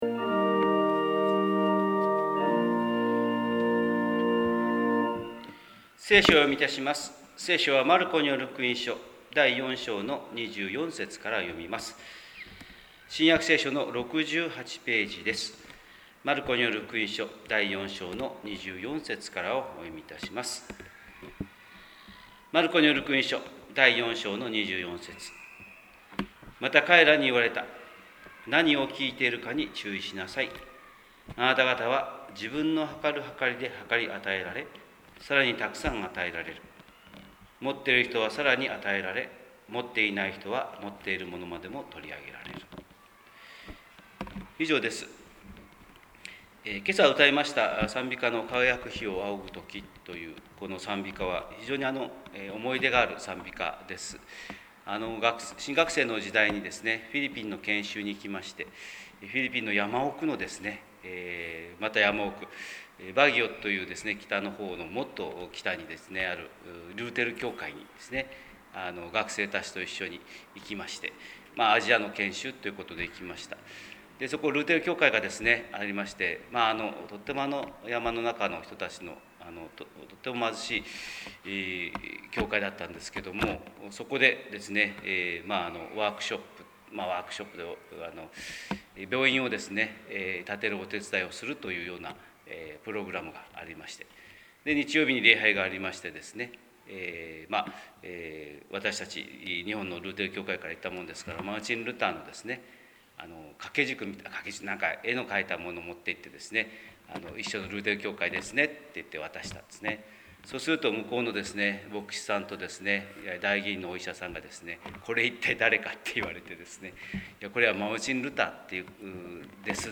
神様の色鉛筆（音声説教）
日本福音ルーテル教会（キリスト教ルター派）牧師の朝礼拝説教です！